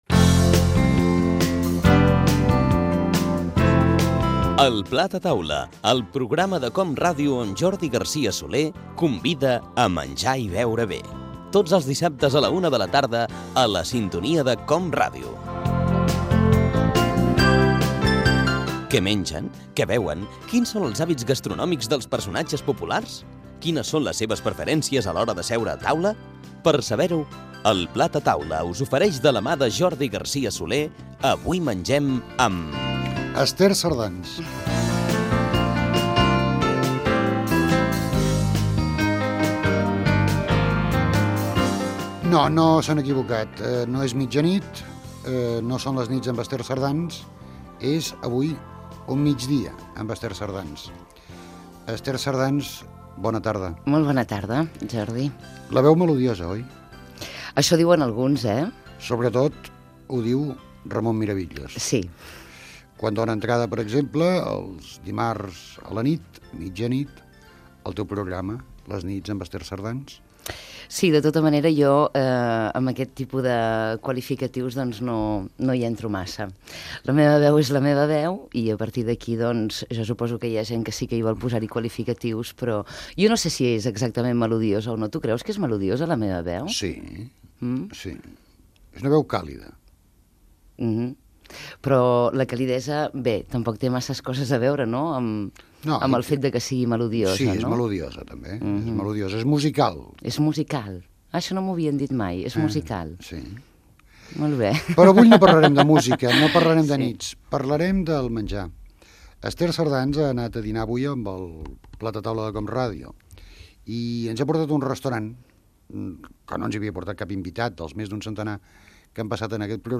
Careta del programa, presentació i fragment d'una entrevista